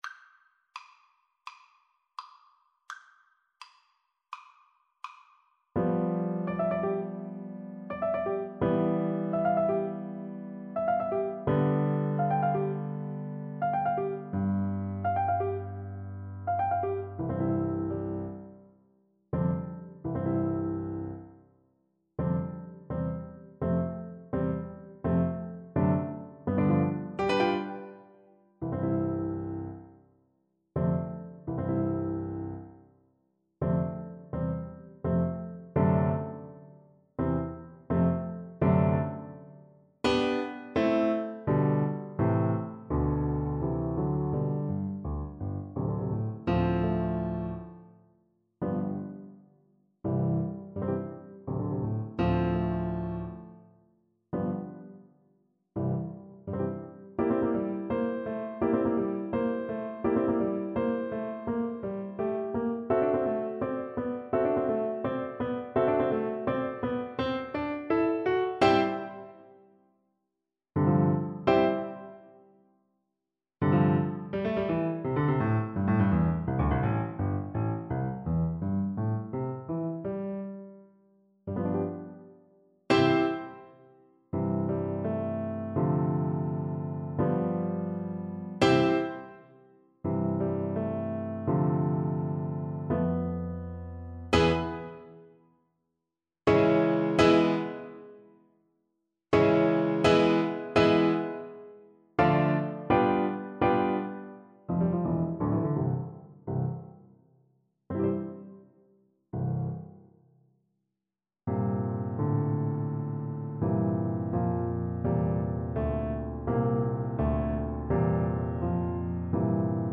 Allegretto scherzando =112
4/4 (View more 4/4 Music)
Classical (View more Classical Cello Music)